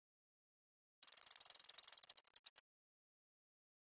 На этой странице собраны звуки шестерёнок разного типа: от мягкого перекатывания до резкого металлического скрежета.
Шестерни в механизме наручных часов